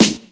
• 00's Rap Steel Snare Drum Sound G# Key 143.wav
Royality free snare single hit tuned to the G# note. Loudest frequency: 2300Hz